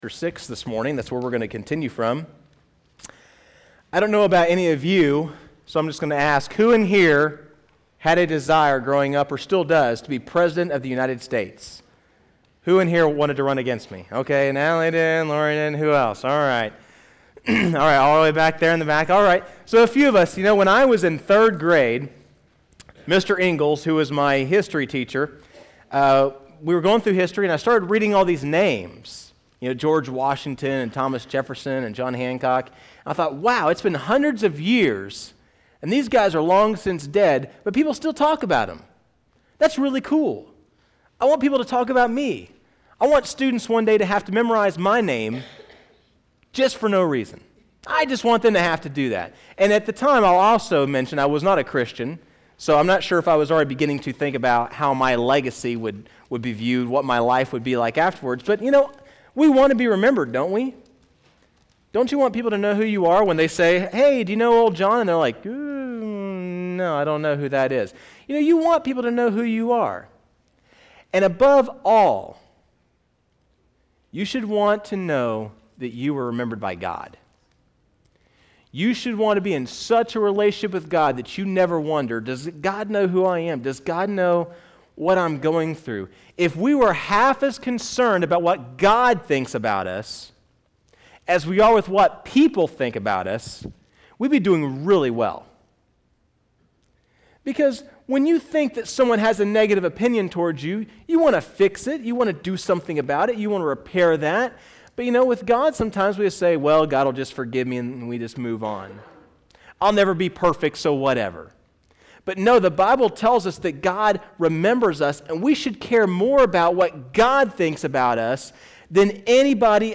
First Baptist Church of Machesney Park Sermon Audio